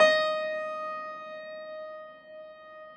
53g-pno15-D3.wav